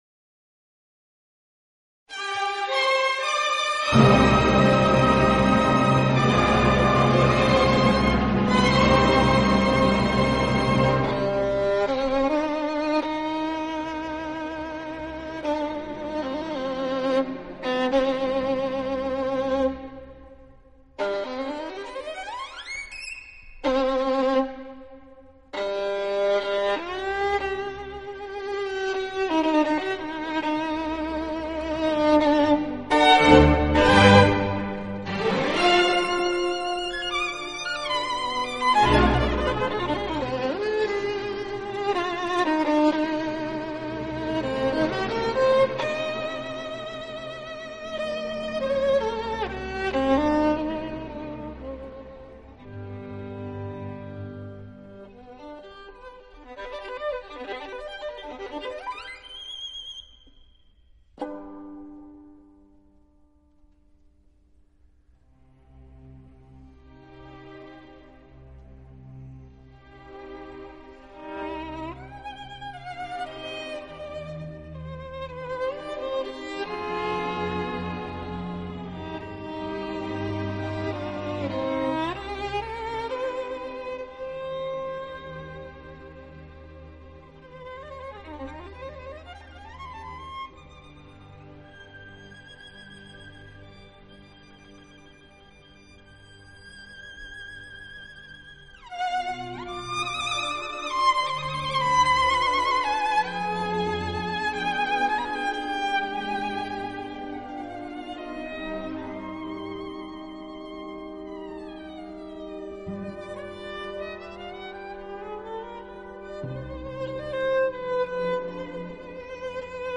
类    型 世界音乐纪念碟
小提琴的质感及音质，音色亦至真至美，伴奏也非常出色。